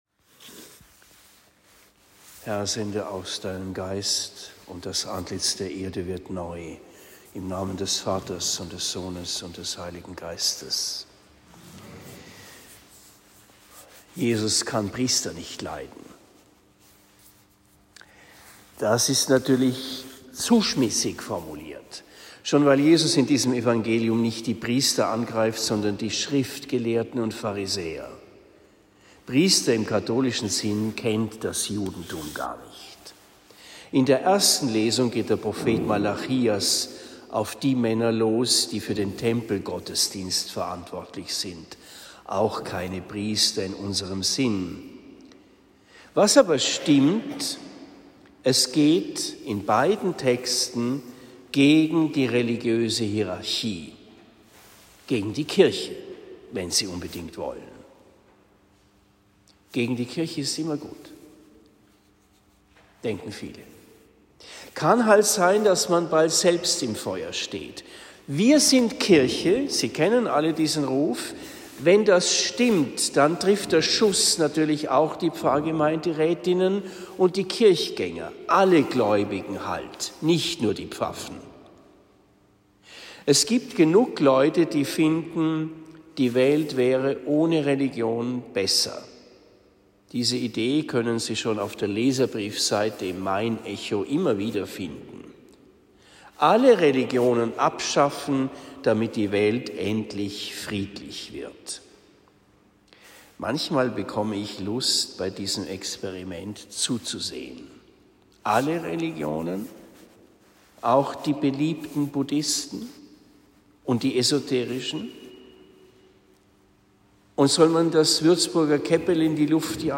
31. Sonntag im Jahreskreis (A) Predigt am 05. November 2023 in Erlenbach